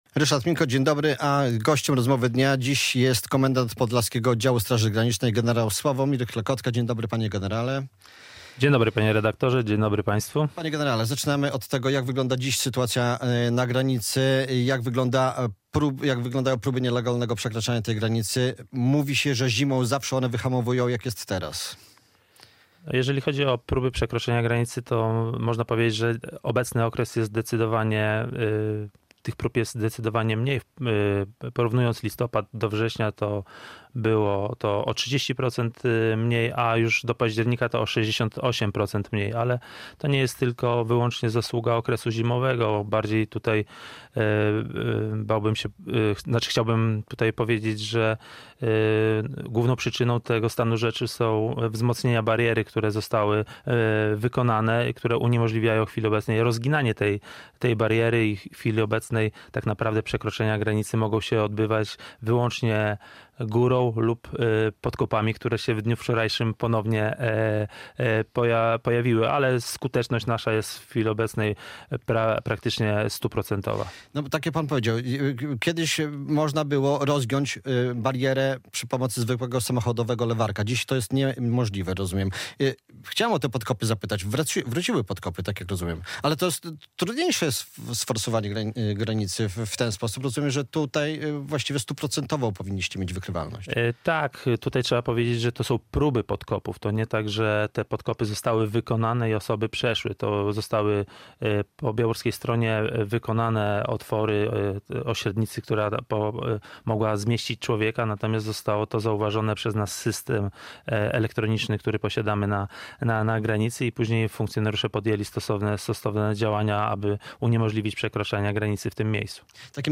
Radio Białystok | Gość | gen. Sławomir Klekotka - komendant podlaskiego oddziału Straży Granicznej